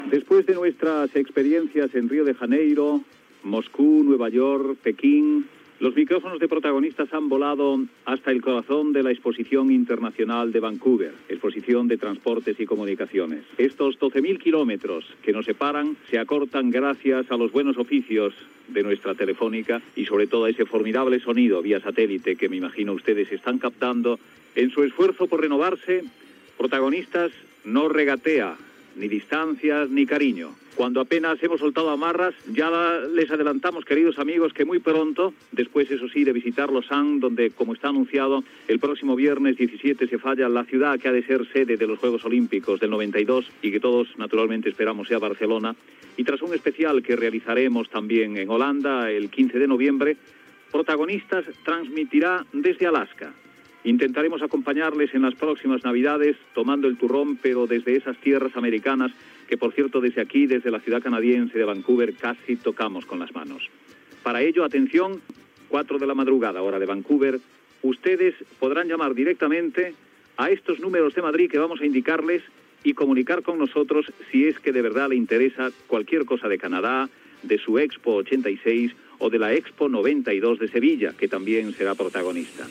Presentació del programa fet des de Vancouver (Canadà), a l'Exposició Internacional de Vancouver.
Info-entreteniment